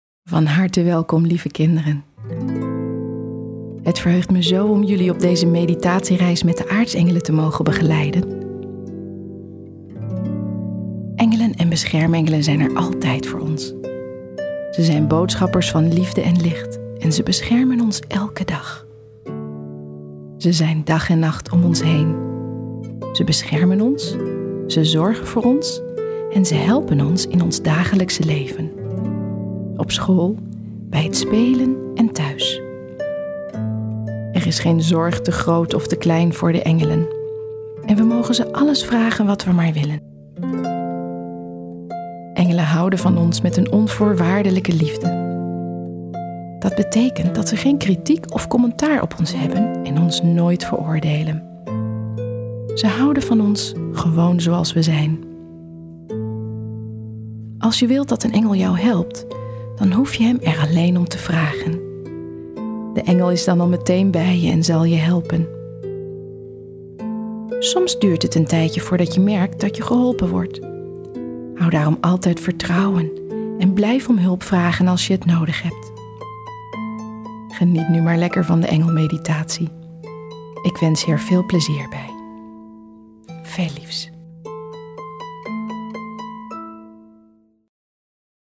luisterboek
3 meditaties voor kinderen
De drie meditaties worden ondersteund door zachte muziek, die de helingskracht van de innerlijke reizen versterkt.